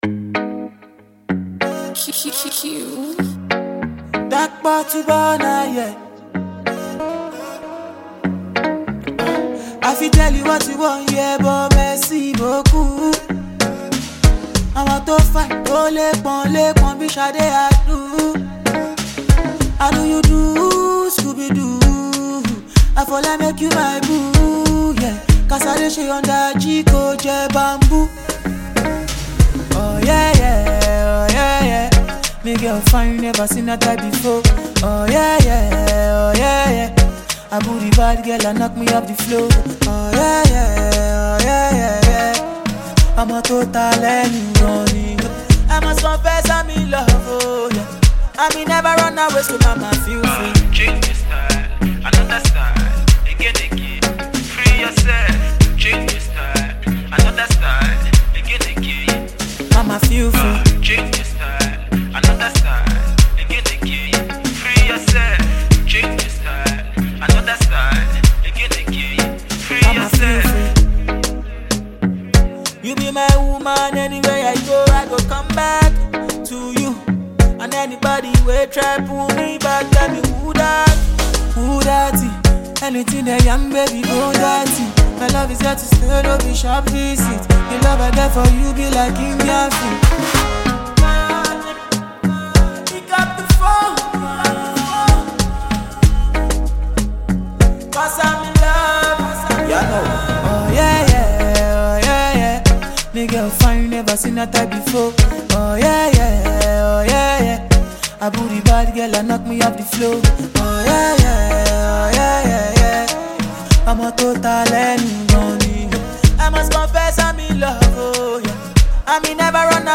and afrobeat rhythms
melodious and memorable sounds